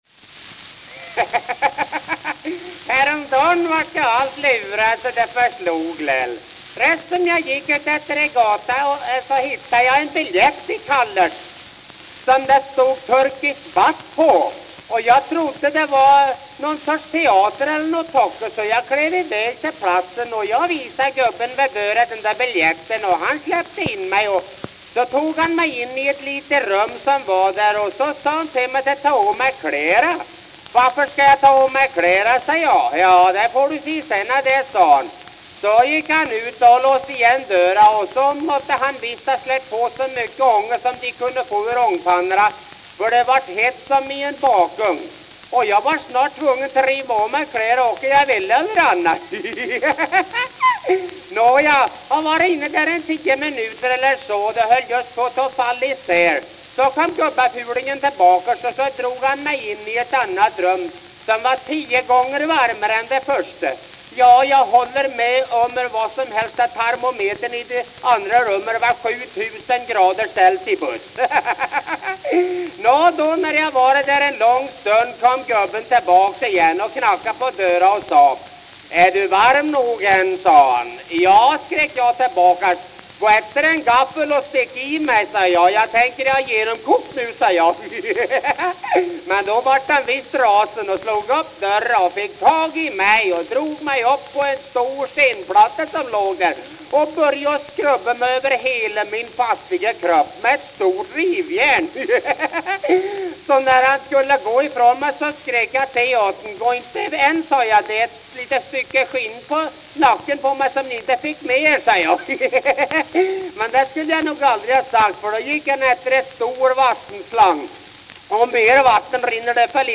New York, New York New York, New York